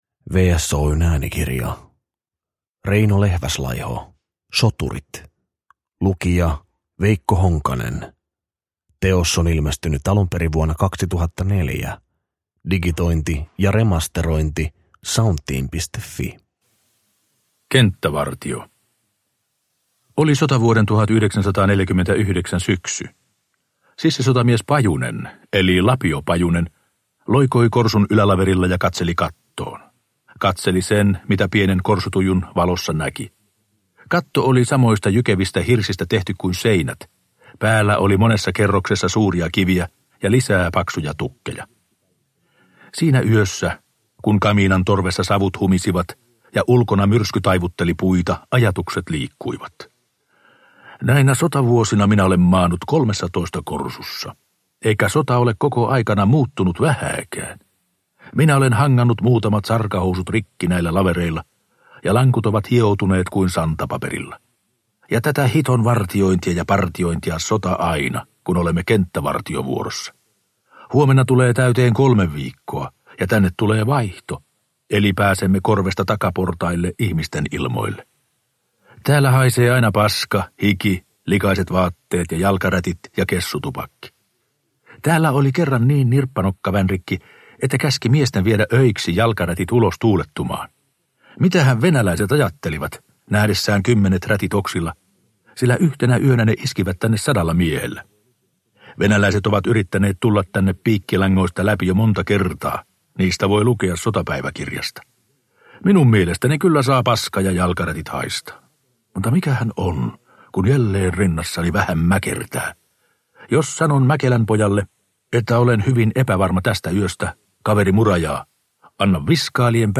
Soturit – Ljudbok – Laddas ner